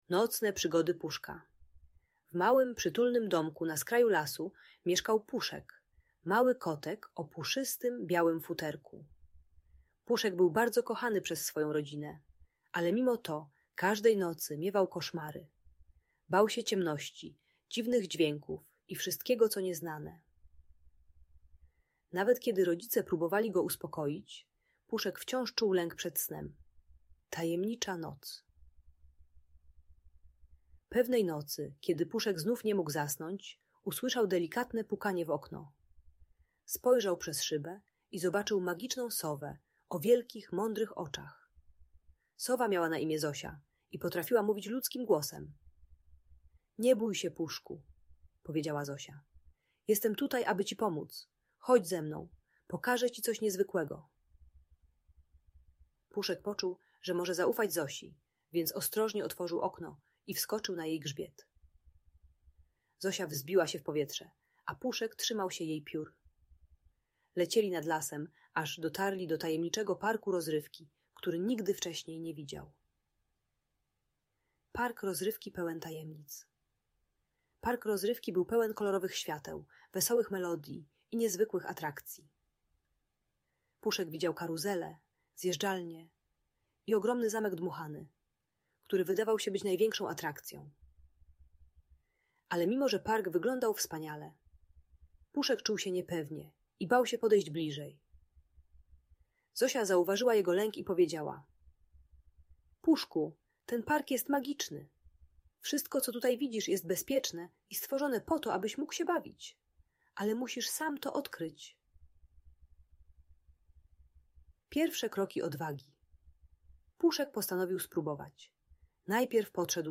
Nocne Przygody Puszka - Magiczna Story dla Dzieci - Audiobajka